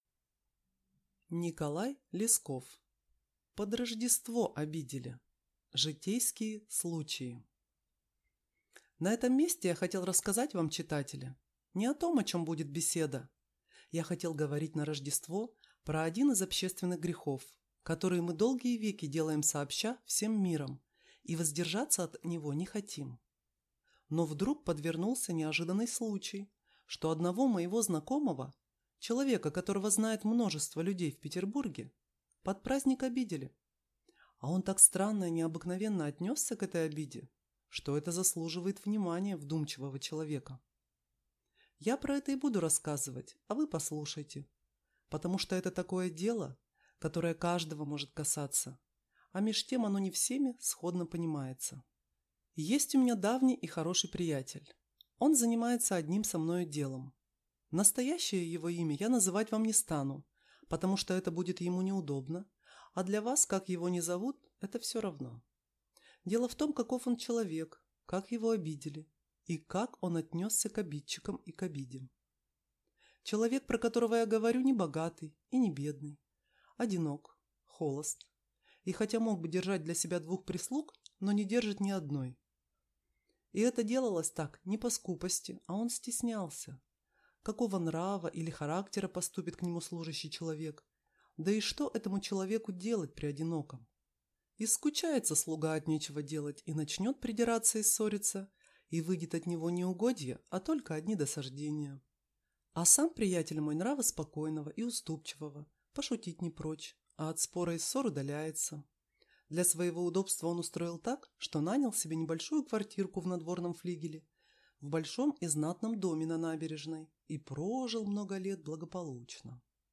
Аудиокнига Под Рождество обидели | Библиотека аудиокниг